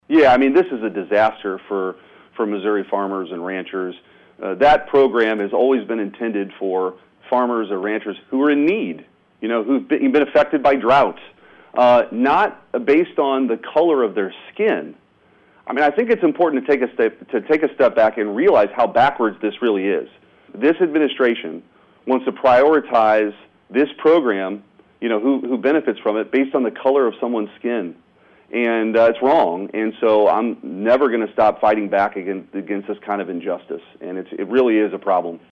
In an interview with KFEQ, Senator Schmitt says that the DEI initiatives are a problem when it comes to relief programs.